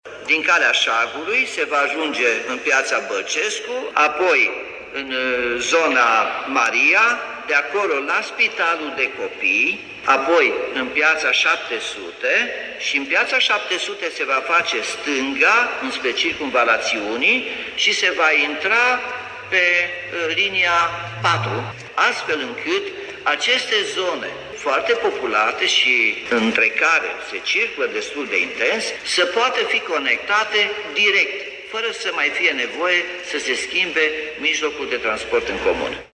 Primarul Nicolae Robu spune că modificarea face parte dintr-un plan mai amplu de redefinire a traseelor mijloacelor de transport în comun.